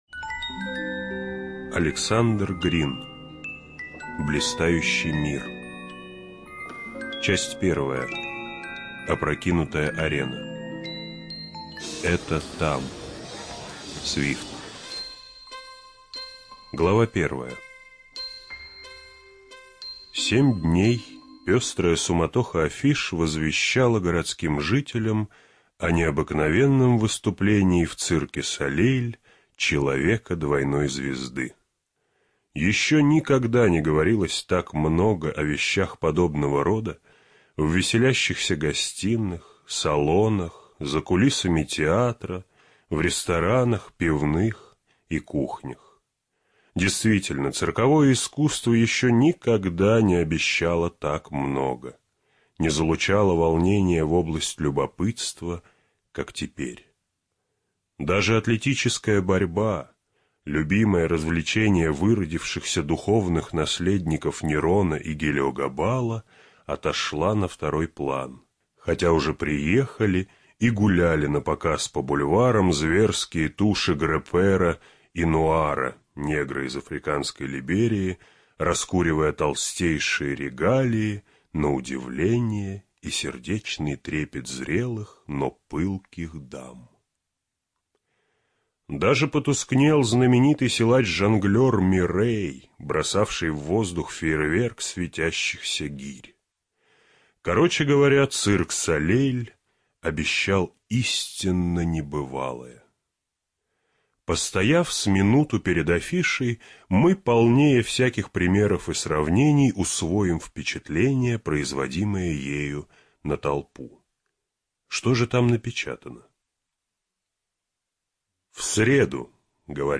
Студия звукозаписиРавновесие